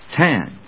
tan /tˈæn/
• / tˈæn(米国英語)